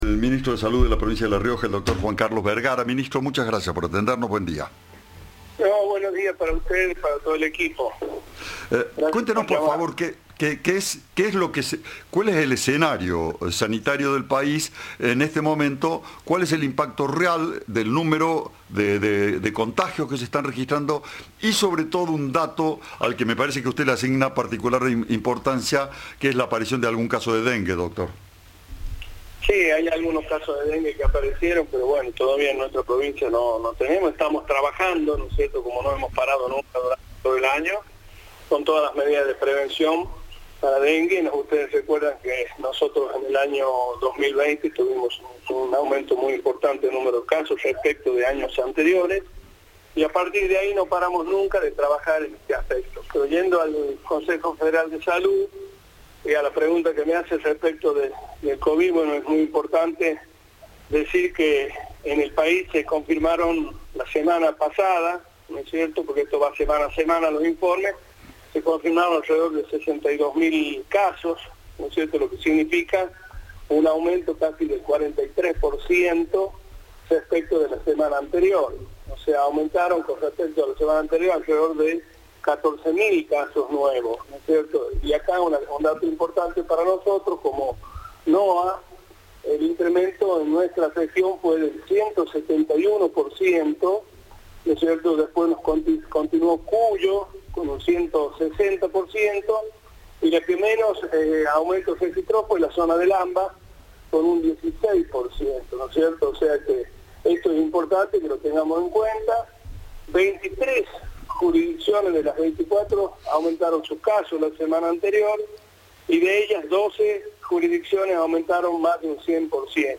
Juan Carlos Vergara, ministro de Salud de La Rioja, participó de la reunión y afirmó a Cadena 3 que el coronavirus "es un tema preocupante en este momento".
Entrevista